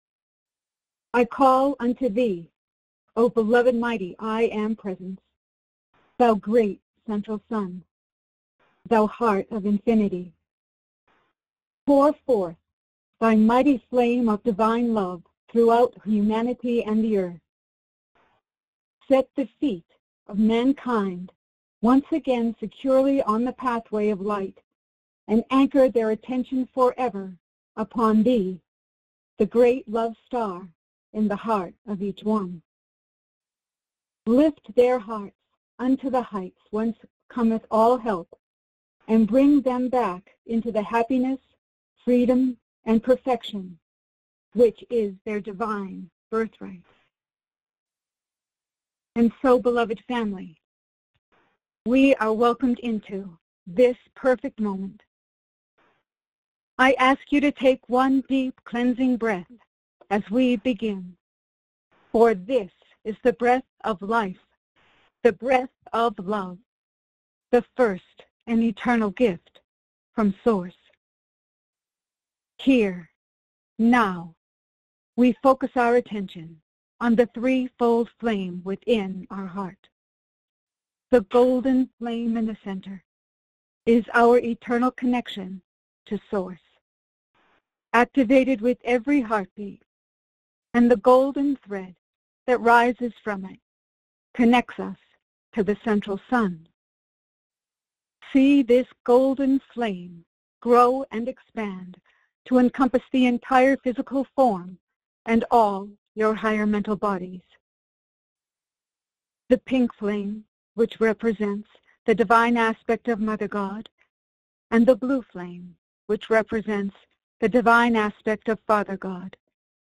These messages were given during our Ancient Awakenings weekly Sunday conference call in Payson, AZ on January 18, 2026.
Meditation – Minute (00:00)
Channeling – Minute (20:26)